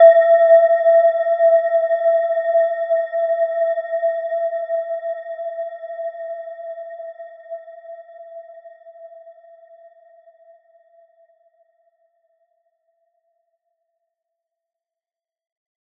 Gentle-Metallic-4-E5-f.wav